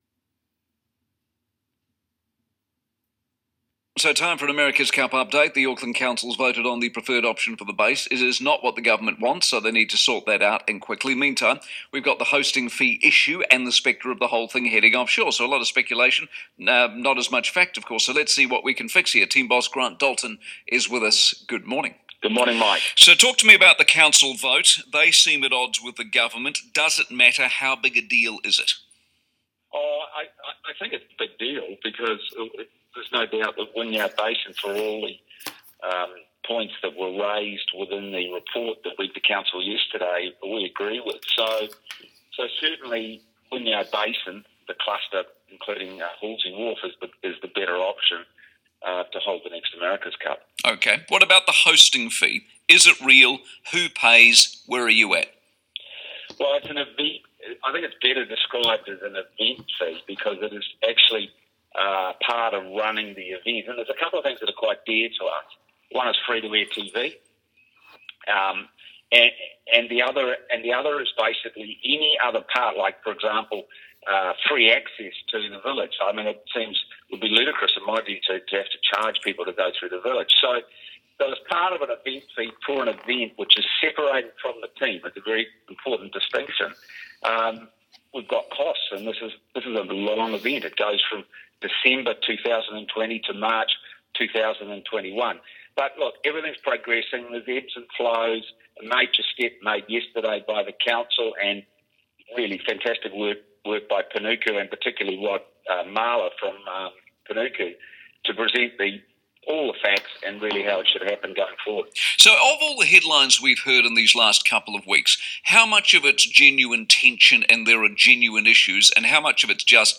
Grant Dalton, ETNZ CEO said in a radio interview on 15 December that he doesn't expect the 2021 America's Cup to go to Italy, saying "I'd be very surprised if that could ever happen.